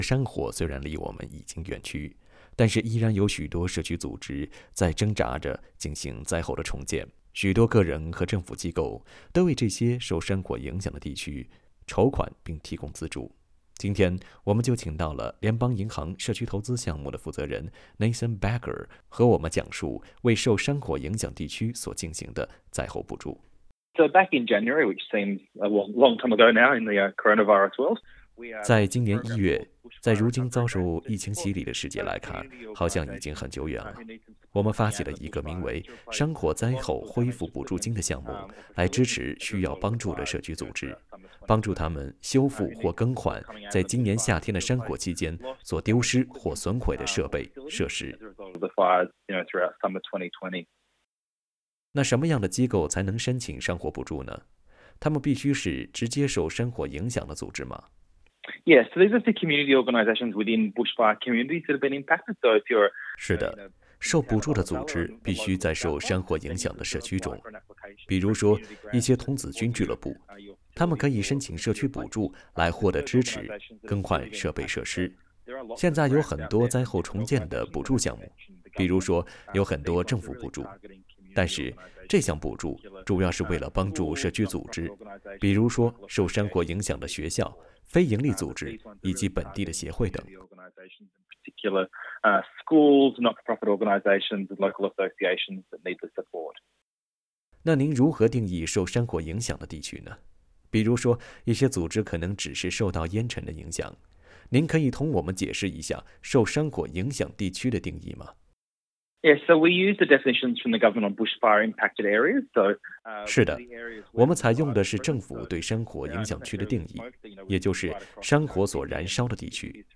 interview_bushfire_grant.mp3